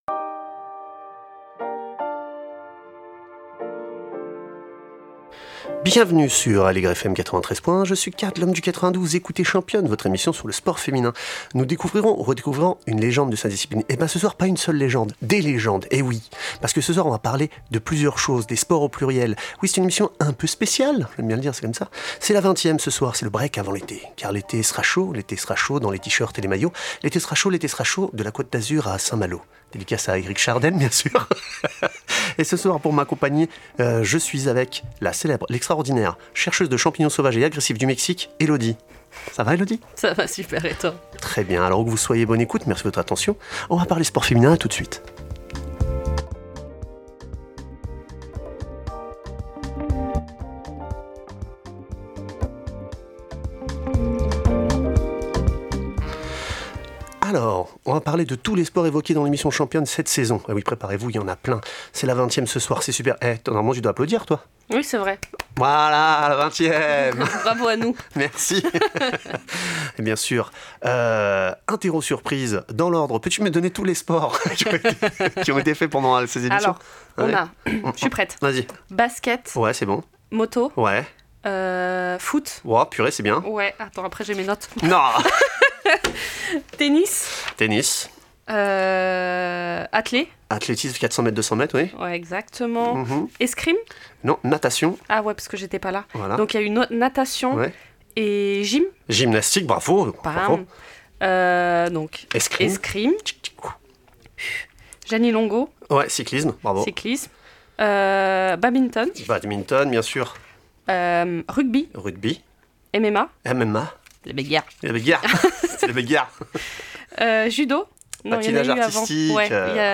Qu’elles soient amatrices, semi-pros ou pros, nous échangeons avec des invitées inspirantes, qui partagent leurs visions et leurs expériences.